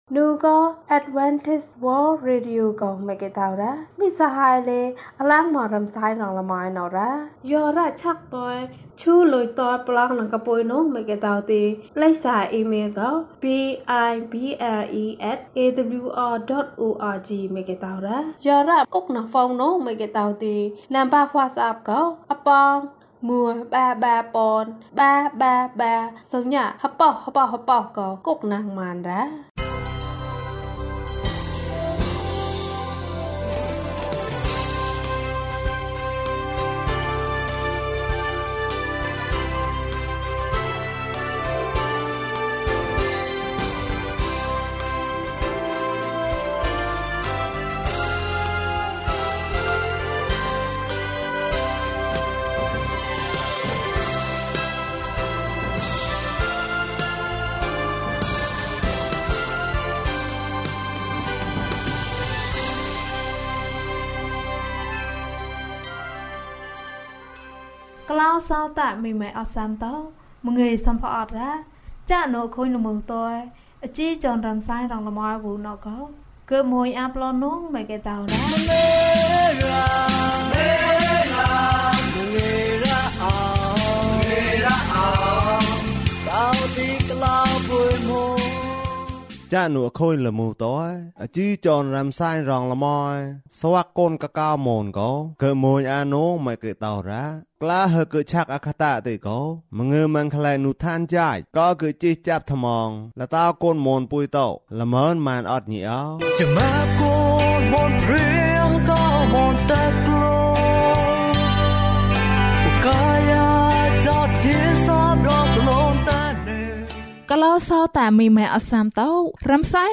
ငါ့ဘဝရဲ့အလင်းရောင်။၀၂ ကျန်းမာခြင်းအကြောင်းအရာ။ ဓမ္မသီချင်း။ တရားဒေသနာ။